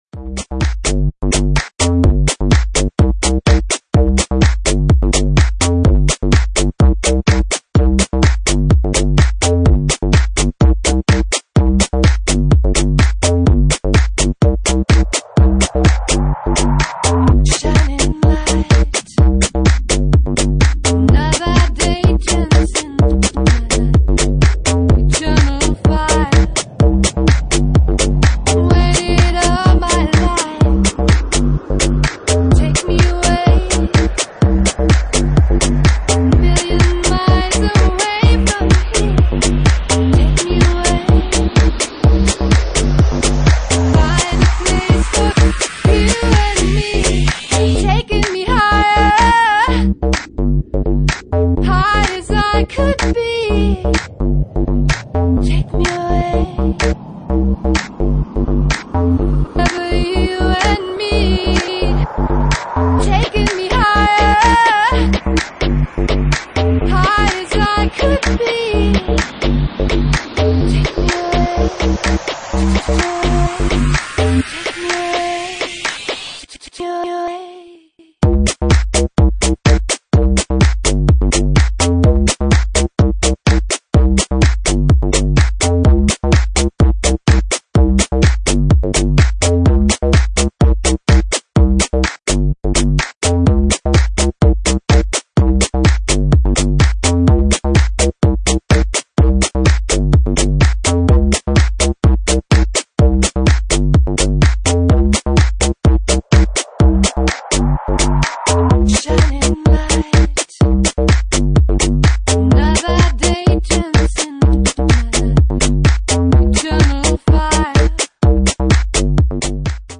Genre:Jacking House
Jacking House at 126 bpm